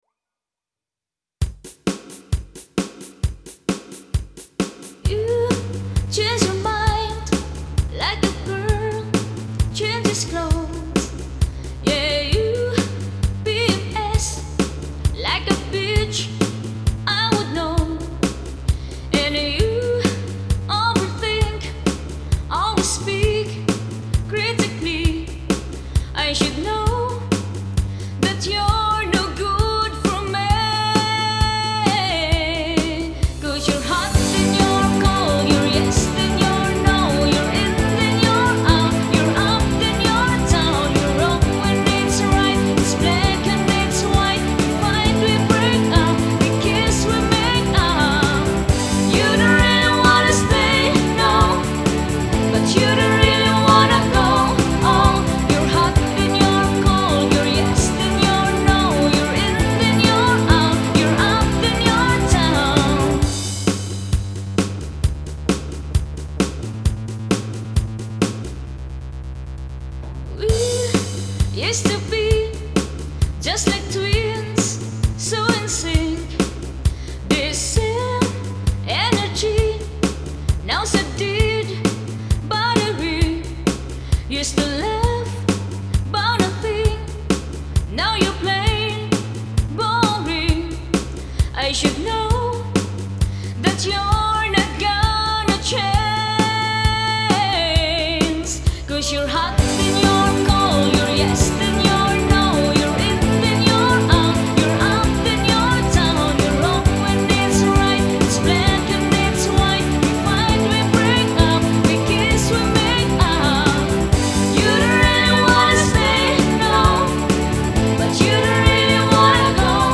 Event Party Band - Hochzeit Band - Party Band